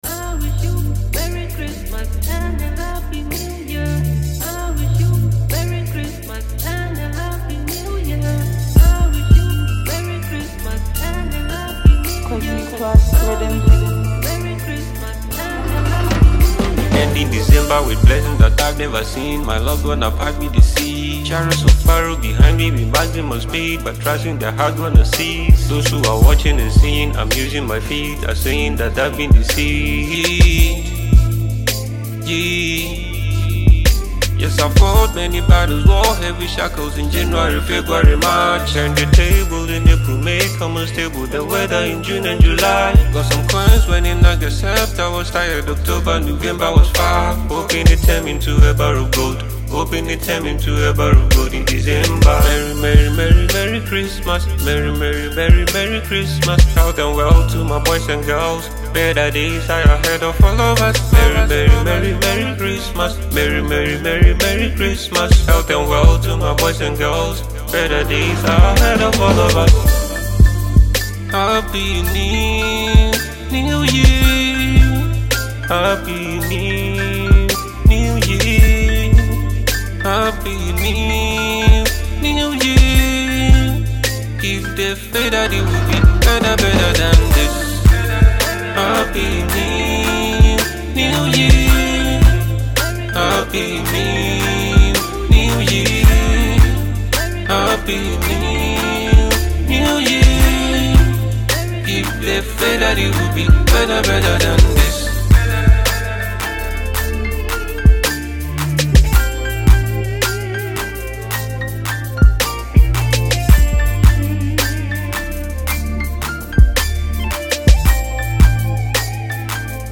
Christmas song
Enjoy this amazing studio track.